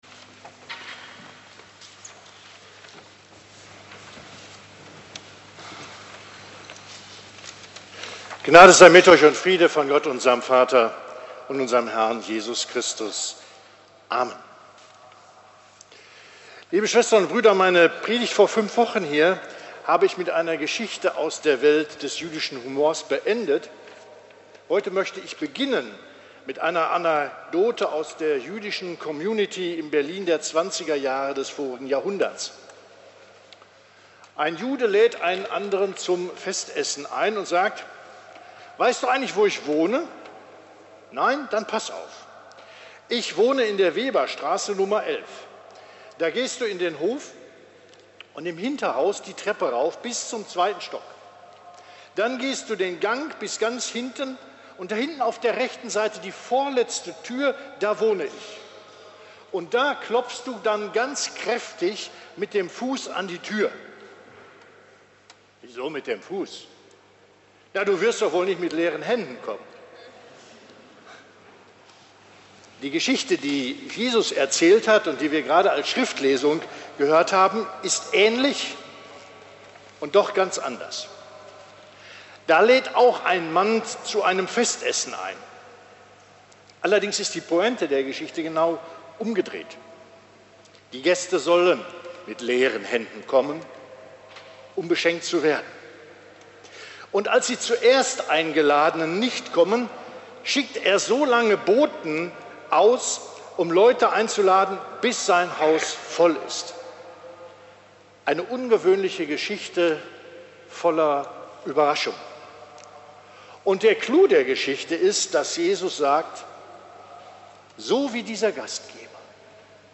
Hören Sie hier die Predigt zu Lukas 14, 15-24